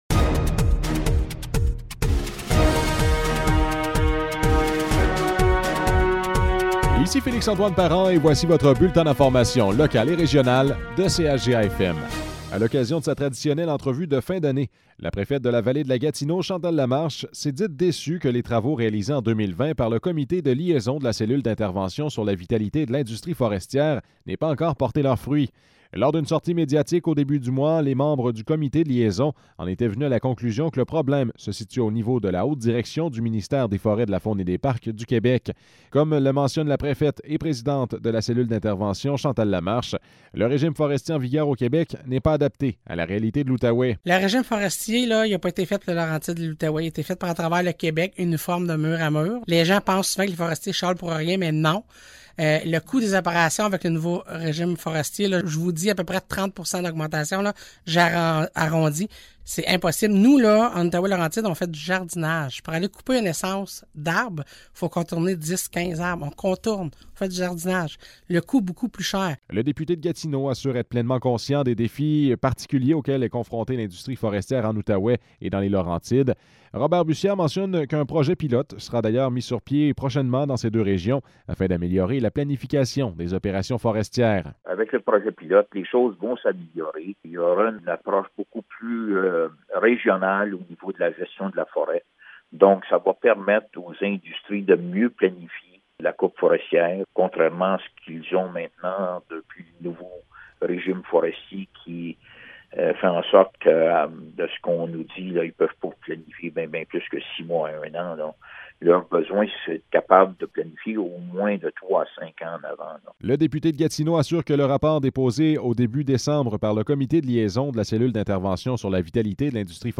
Nouvelles locales - 23 décembre 2020 - 12 h